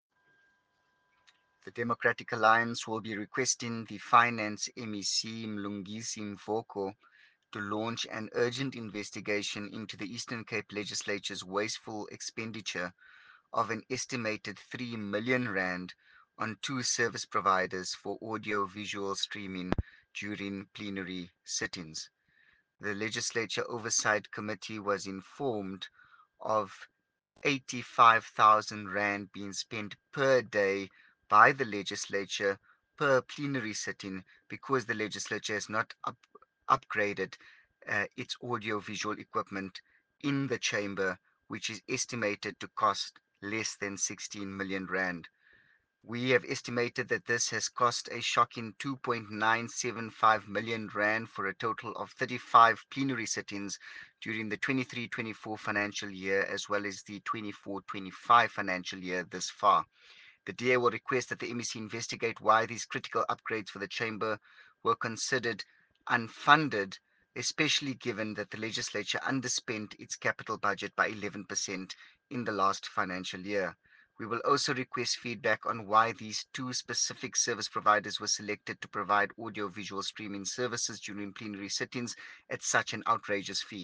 soundbite by Yusuf Cassim MPL